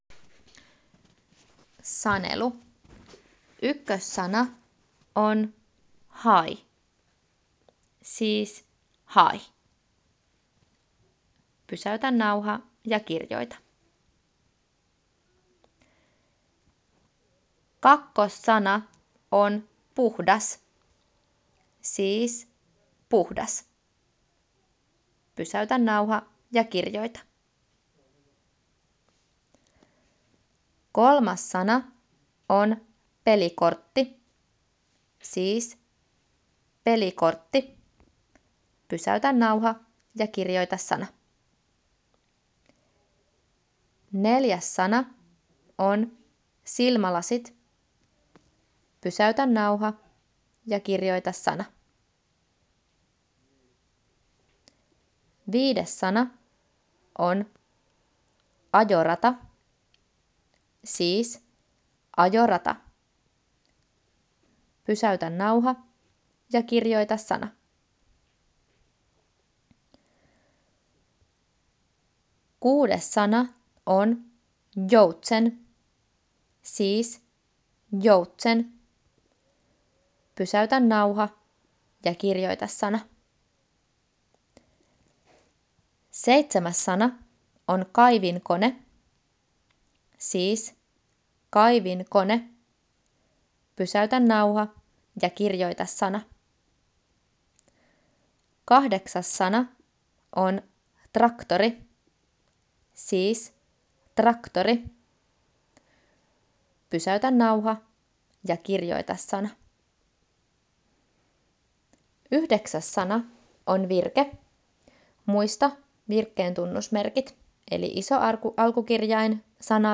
Kahdeksan ensimmäistä kohtaa ovat sanoja ja kaksi viimeistä (9. ja 10.) virkkeitä. 4.Kun olet tehnyt sanelun, ota kuva ja palauta kansioon tai lähetä kuva open puhelimeen.